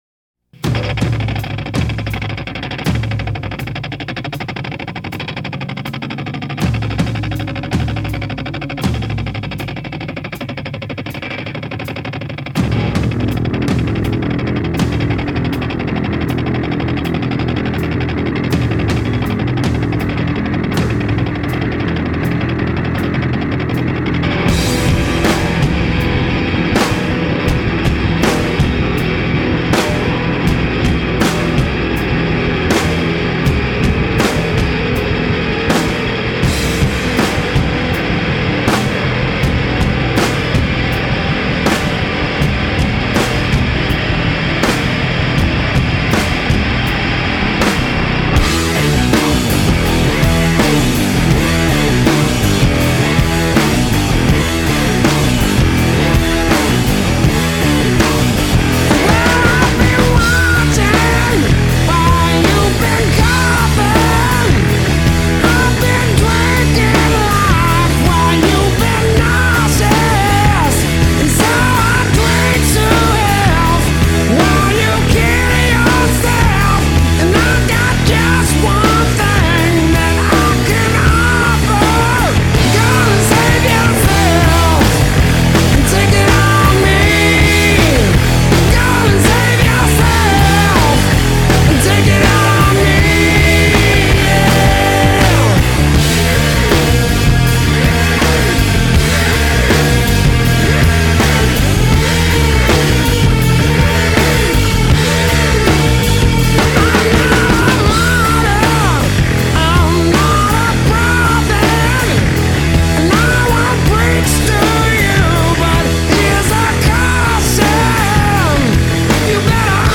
Tags: alternative rock random sounds funny sounds